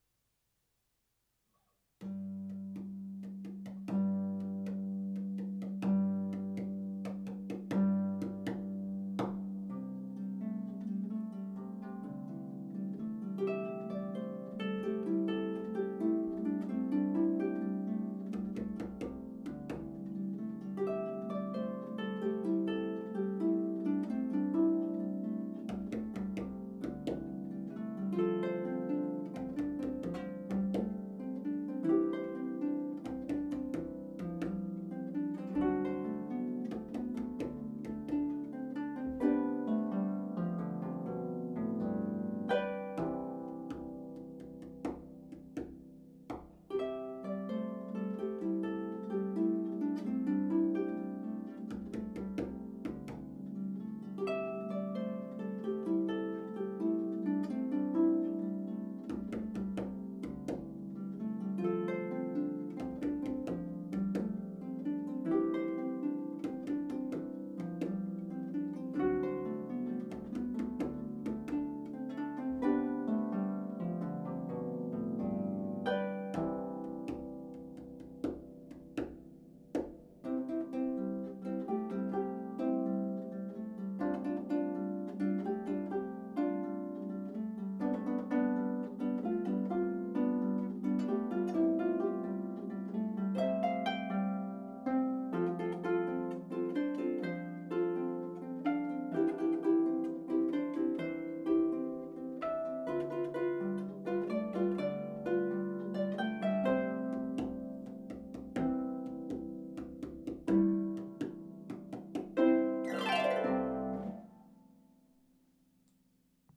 an original piece for solo lever or pedal harp